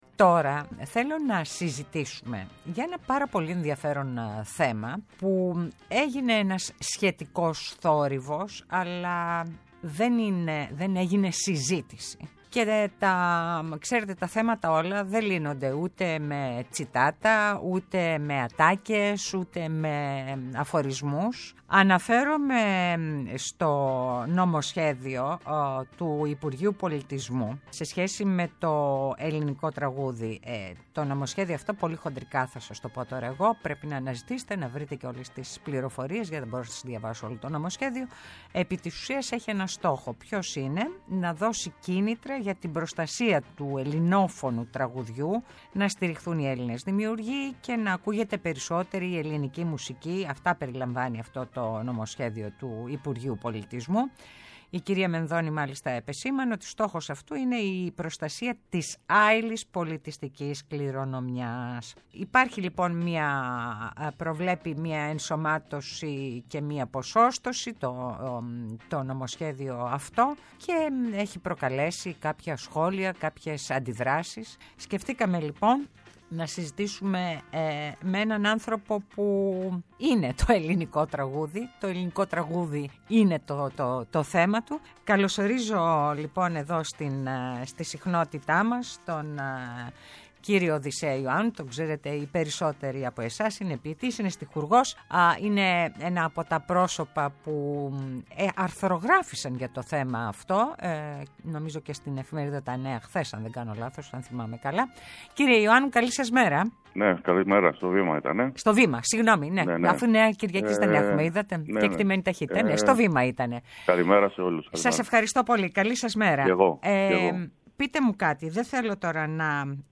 μιλά ο Στιχουργός Οδυσσέας Ιωάννου για την υποχρέωση της Πολιτείας και όχι ιδιωτικών επιχειρήσεων να προστατεύουν την κληρονομιά αυτή.